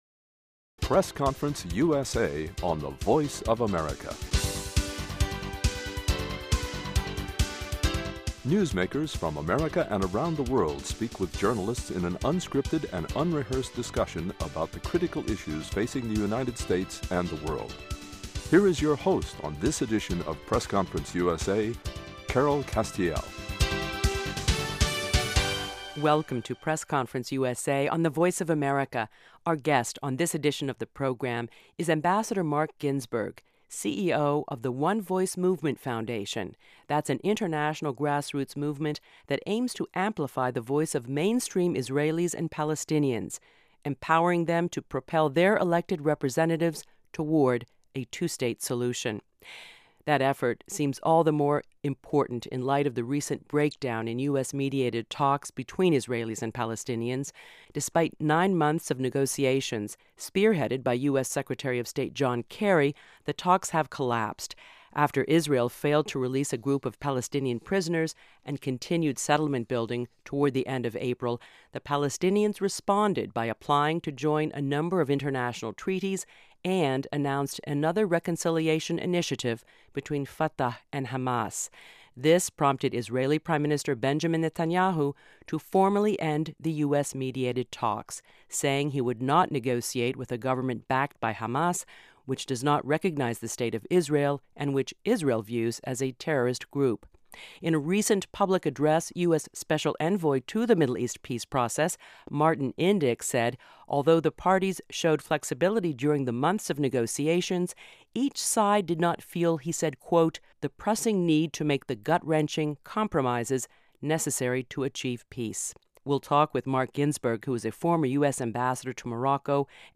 AMBASSADOR MARC GINSBERG A candid conversation with Ambassador Marc Ginsberg, former US Ambassador to Morocco, currently CEO of OneVoice, an organization which aims to amplify the voices of mainstream Palestinians and Israelis to achieve a two-state solution. Ambassador Ginsberg analyzes the factors that contributed to the recent collapse of the US-mediated peace talks and discusses the work and impact of the OneVoice Movement Foundation.